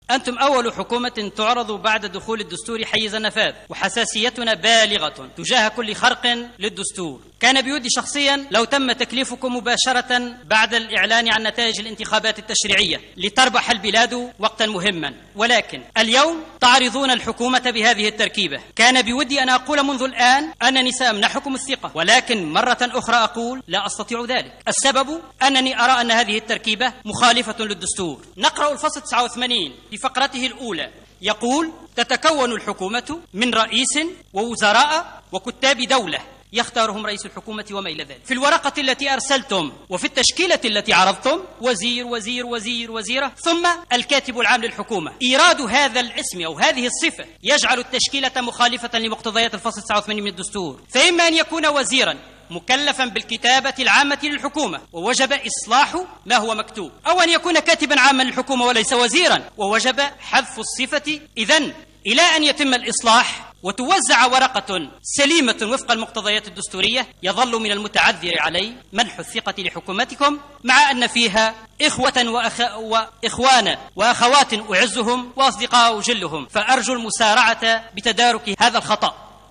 قال النائبْ بمجلس نوّاب الشعب الحبيب خضّر خلال مُداخلة لهُ مساء اليوم في الحصّة المسائية من الجلسة أمام رئيس الحكومة المُكلّف و أعضاء حُكومته أن التشكيلة التي يعرضها الحبيب الصيد امام المجلس للمصادقة عليها مخالفة لمقتضيات الدستور مؤكدا أنه لن يمنح ثقته لهذه الحكومة إلا إذا قامت بتصحيح هذا الخطأ.